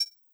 Modern UI SFX
GenericButton9.wav